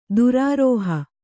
♪ durārōhōha